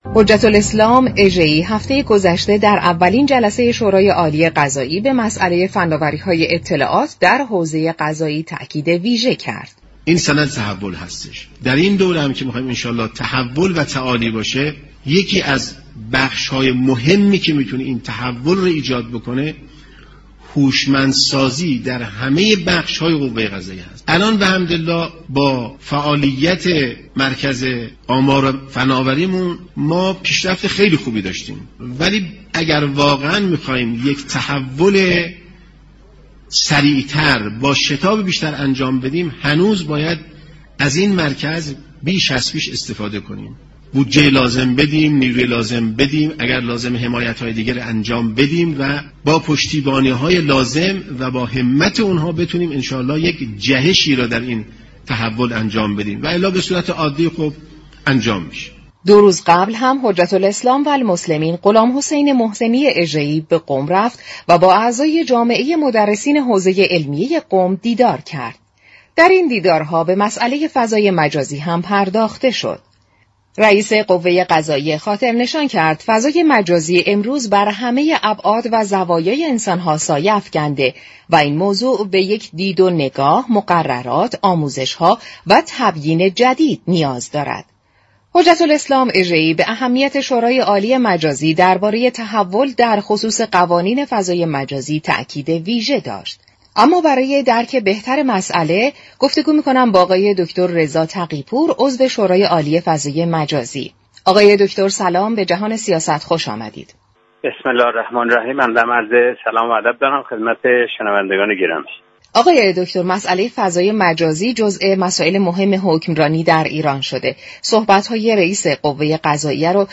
به گزارش شبكه رادیویی ایران، رضا تقی پور عضو شورای عالی فضای مجازی در برنامه «جهان سیاست» درباره فضای مجازی و مهم ترین اولویت های قانونگذاری آن گفت: با استفاده بهینه از فضای مجازی، می توان حاكمیت و حكمرانی را در سایر بخش ها ساماندهی كرد.